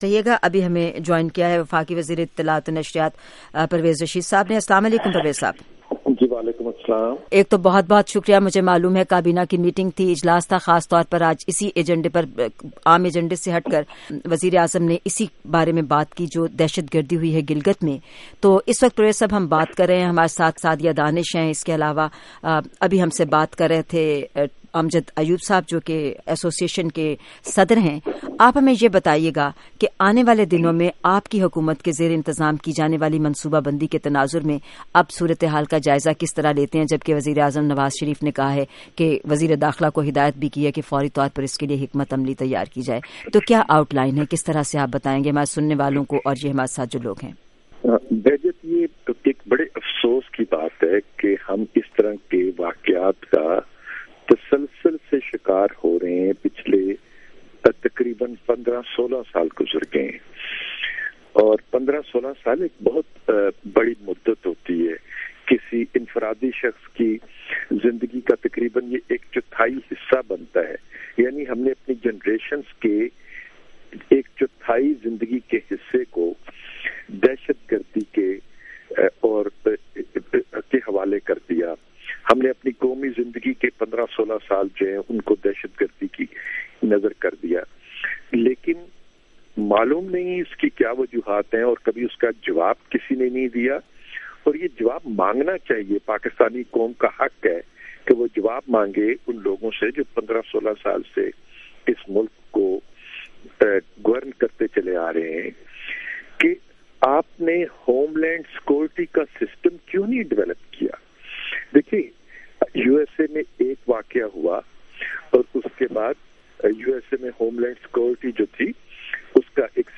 Excl. interview with Information minister, Pervaiz Rasheed